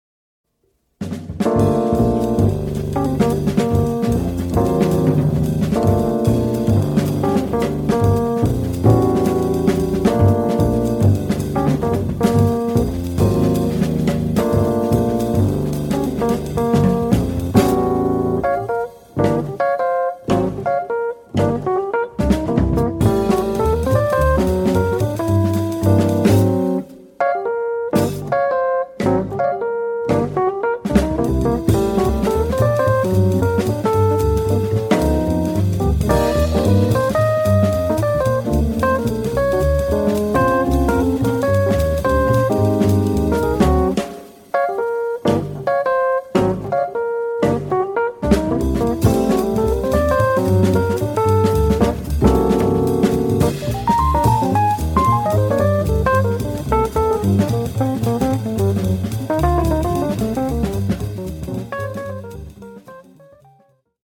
ジャズの中でもよく親しんでいるハードバップ風な曲を書いてみました。